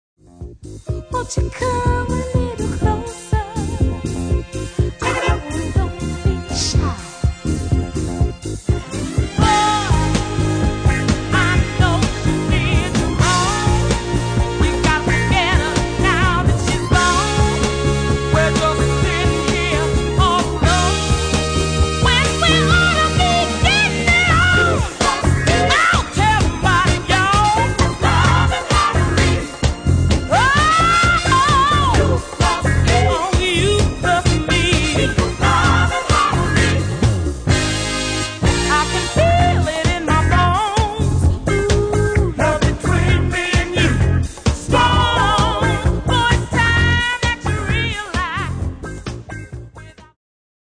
Genere:   Disco Funky